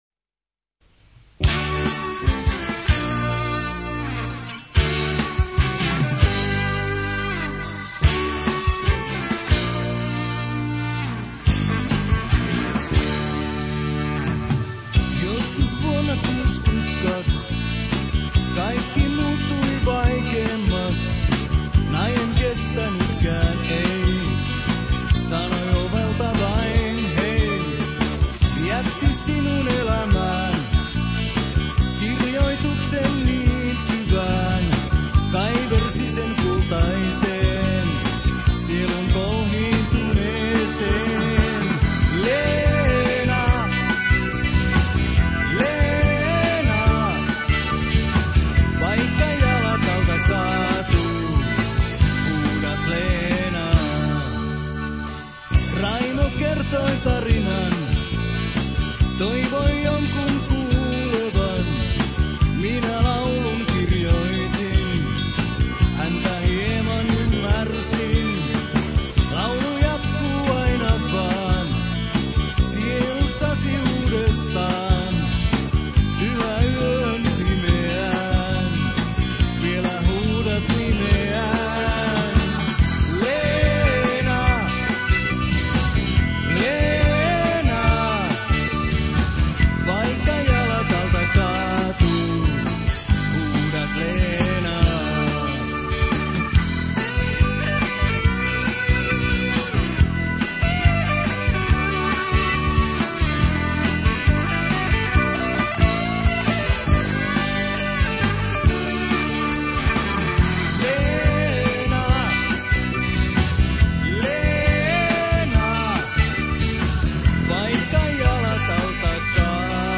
laulu, kitara
basso
rummut
taustalaulu
kosketinsoittimet
dobro
piano